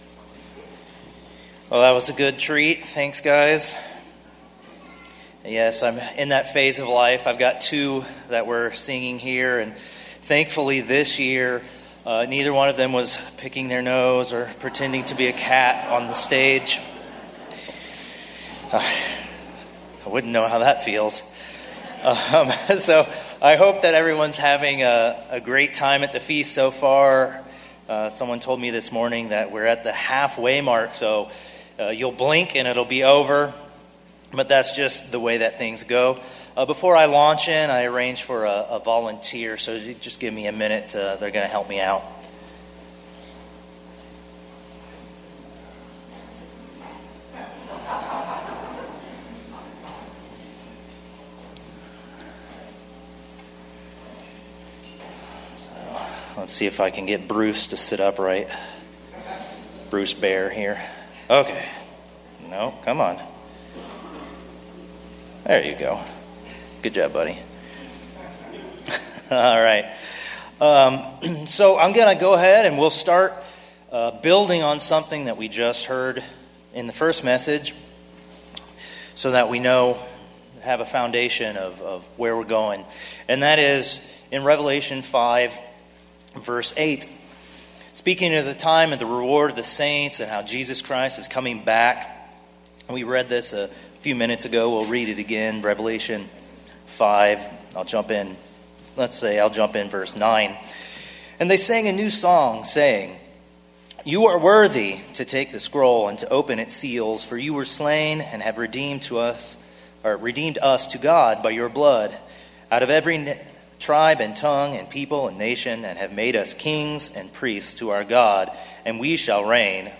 Given in Glacier Country, Montana 2025